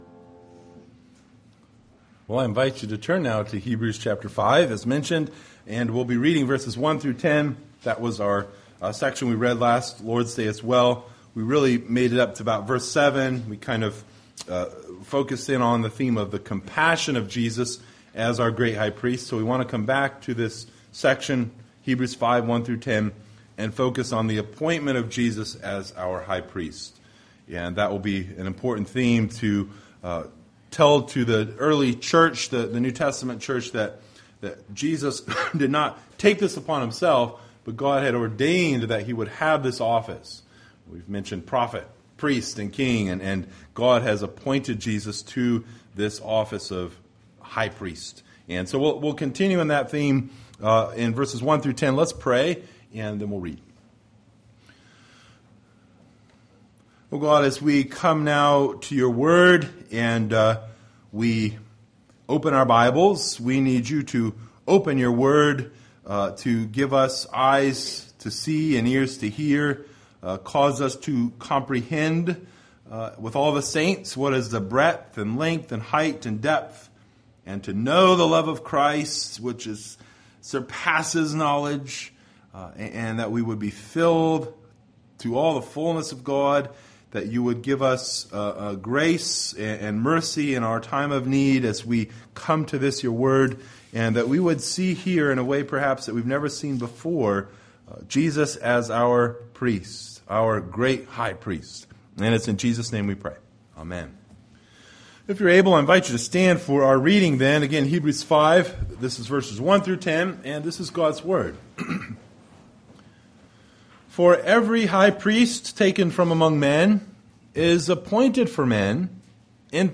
Hebrews Passage: Hebrews 5:1-10 Service Type: Sunday Morning Related « The Holy Scriptures Of God and Of the Holy Trinity